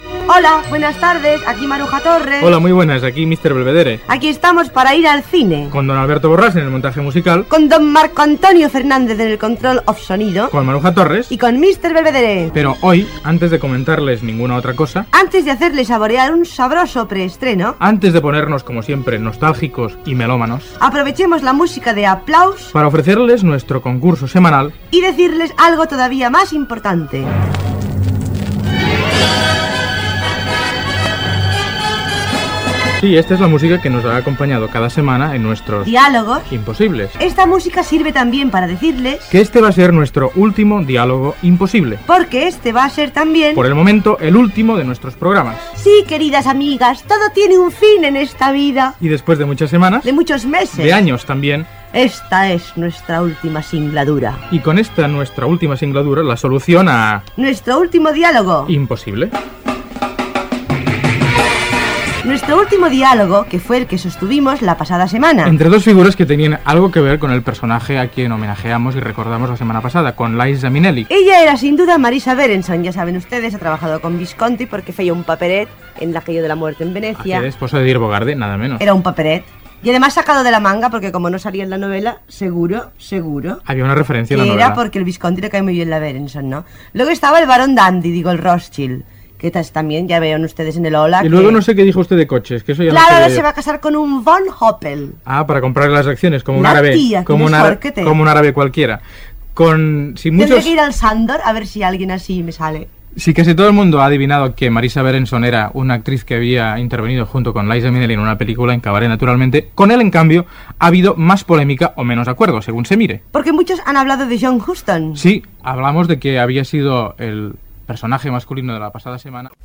Presentador/a
Figueras, Jaume
Torres, Maruja